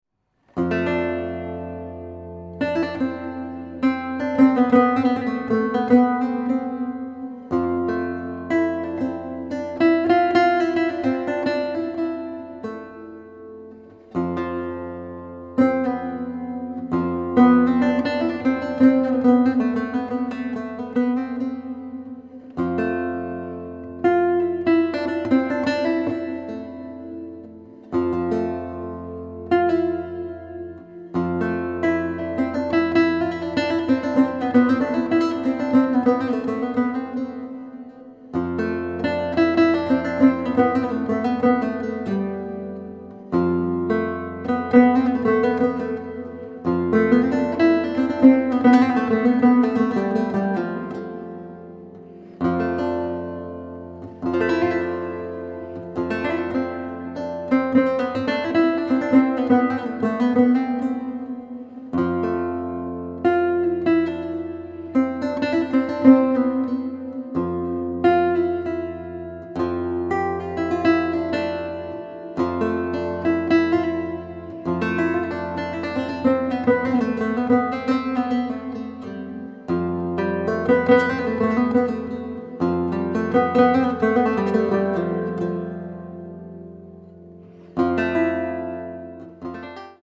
lute & oud player from Japan
Contemporary